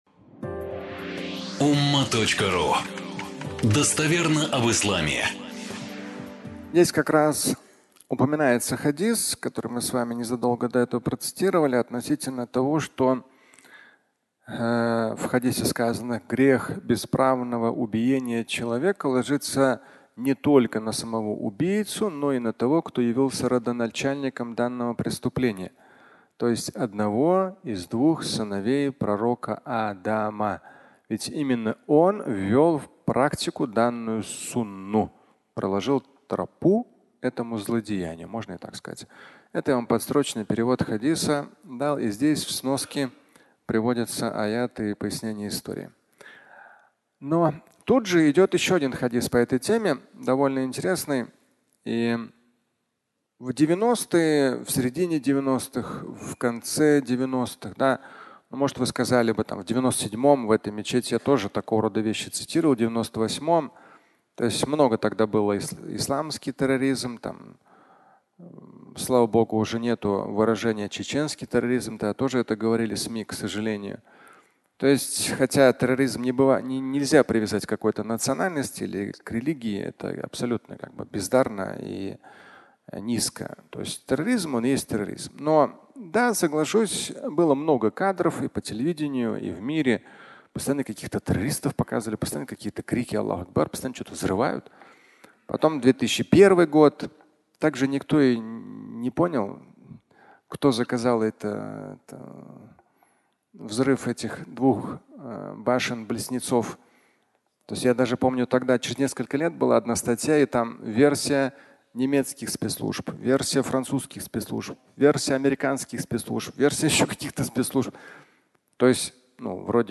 Фрагмент пятничной лекции